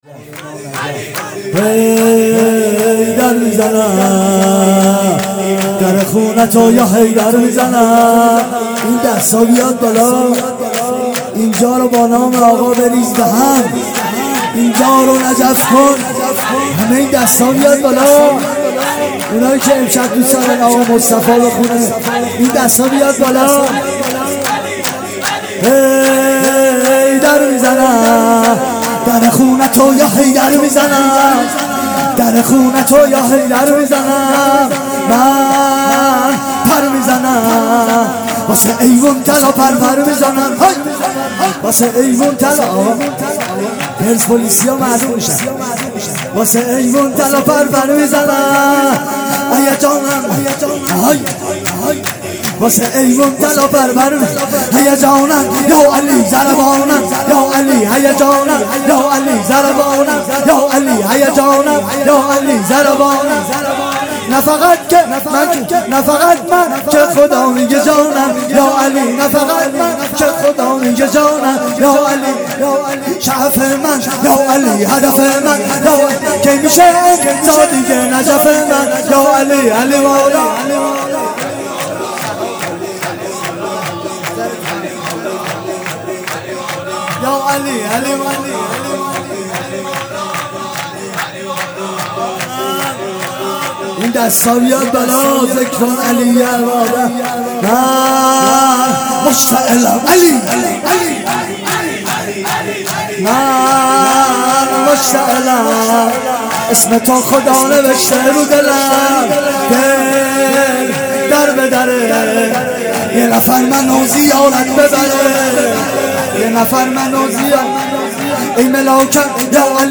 بخش‌چهارم-سرود